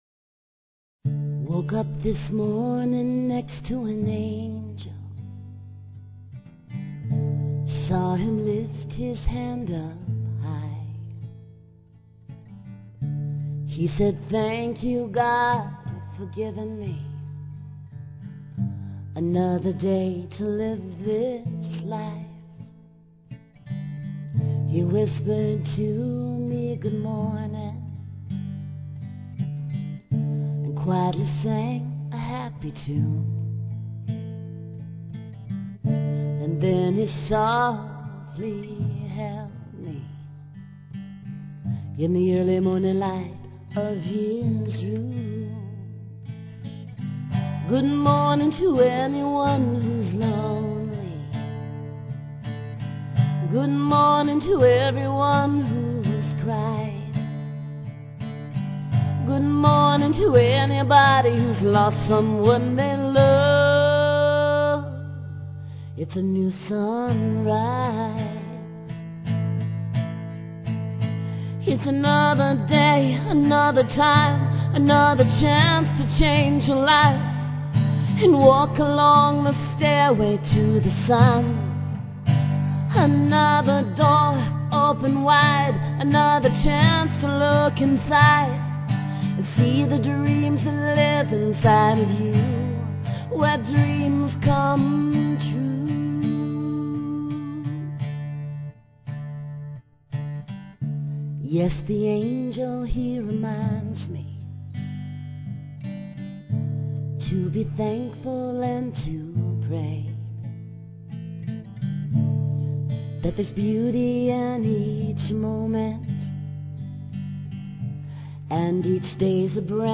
Talk Show Episode, Audio Podcast, Connecting_Spirit_Together and Courtesy of BBS Radio on , show guests , about , categorized as